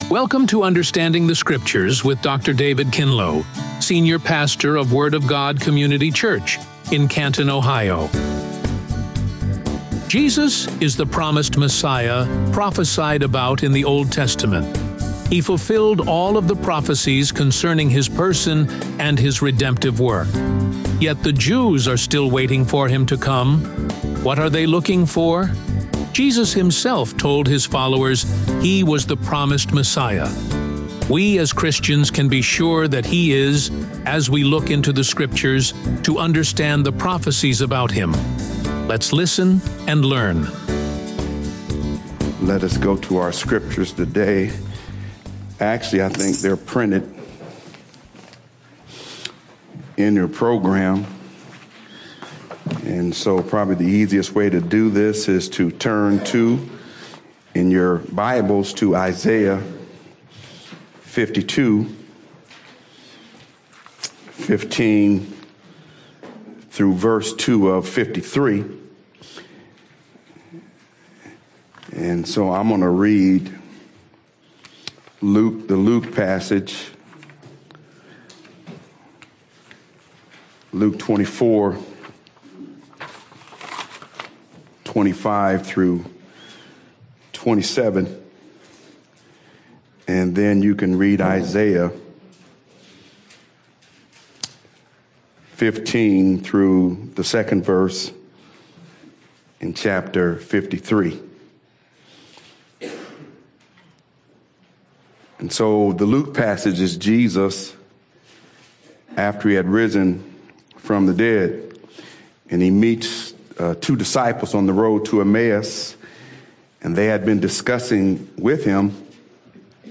Radio Sermons | The Word of God Community Church